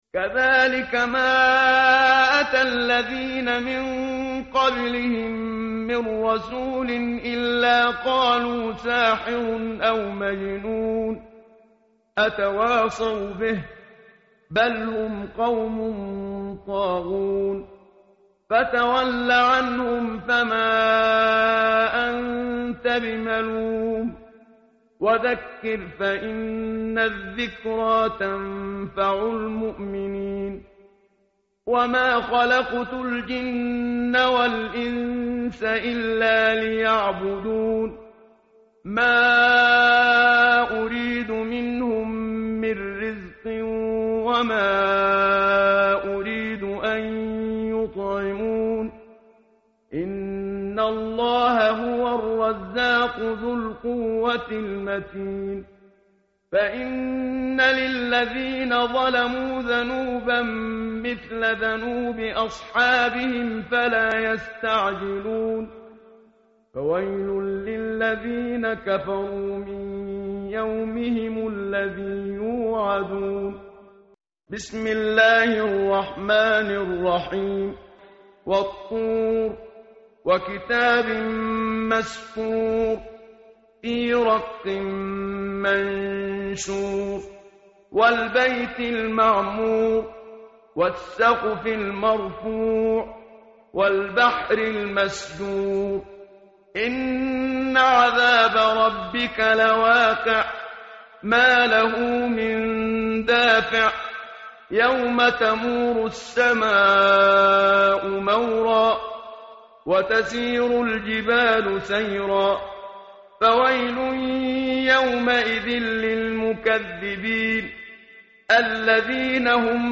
tartil_menshavi_page_523.mp3